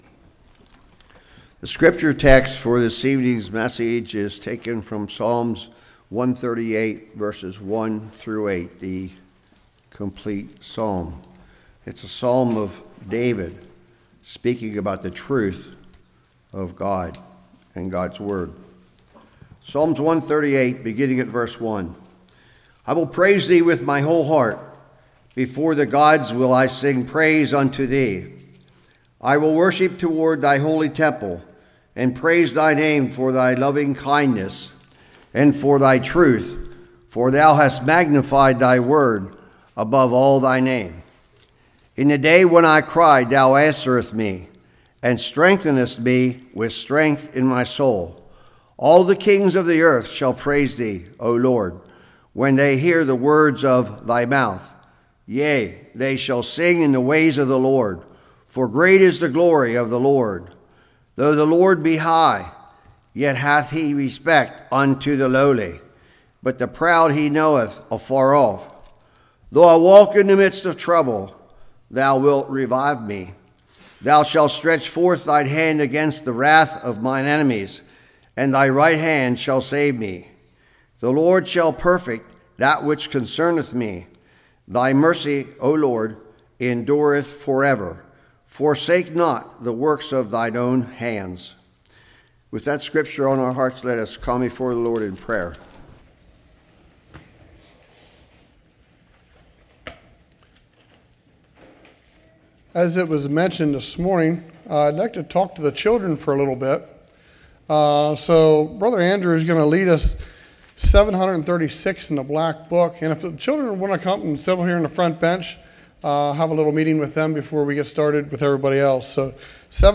Psalms 138 Service Type: Evening God views us as very important Trust and dependence Thanksgiving and praise What is God’s calling to you?